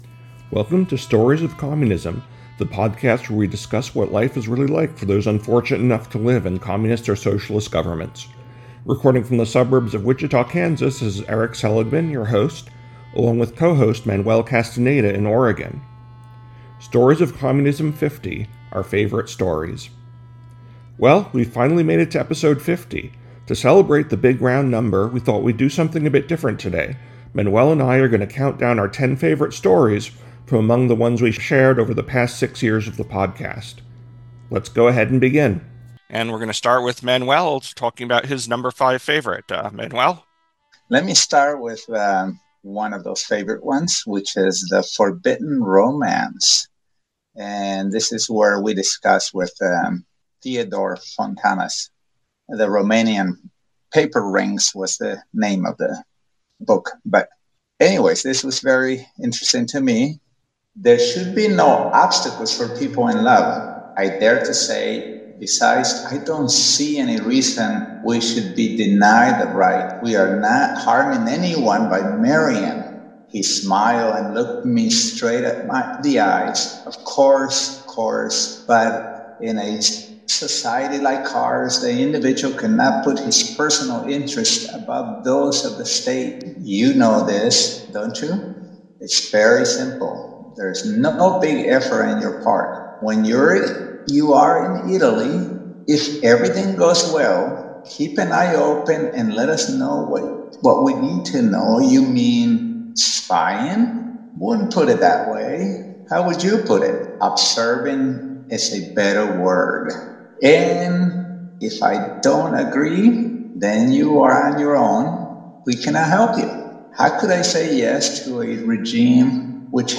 <BTW, if you’re reading this in our show notes, as you’ll hear, the audio doesn’t precisely follow this original script, as we tried to use each one as a kickoff for some on-the-fly discussion.